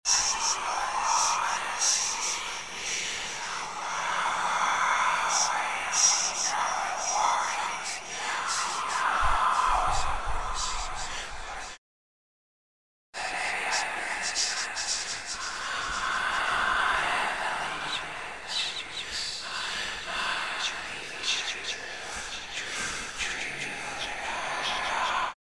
На этой странице собраны разнообразные звуки шепота: от загадочного женского до мужского нашептывания.
страшный мистический шепот